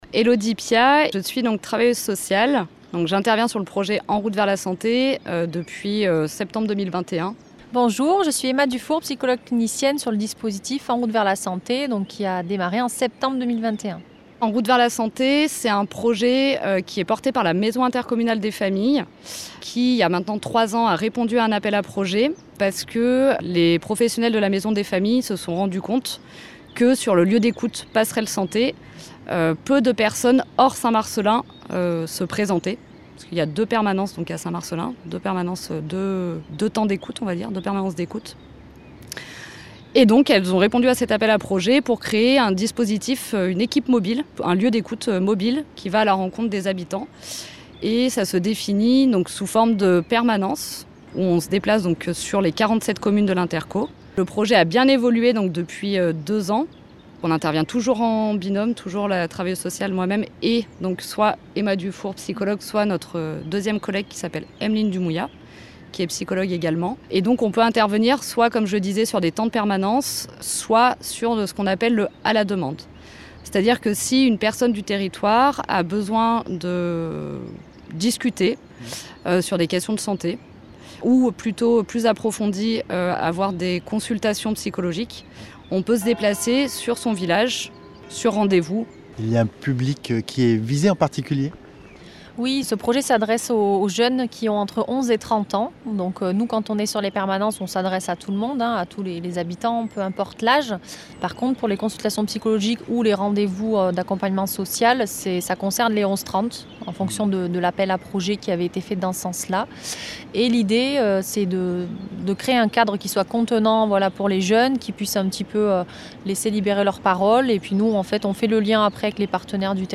Lors d’une étape à Pont en Royans, nous avons rencontré l’équipe de “En route vers la santé” sillonnant le territoire de St Marcellin Vercors-Isère communauté et se tenant à disposition des 11-30 ans pour des temps de consultations.